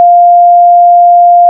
让我们制作一个更高频率（700 Hz）的另一个信号y2
y2 = numpy.sin(2*numpy.pi*700*t)                     # pure sine wave at 700 Hz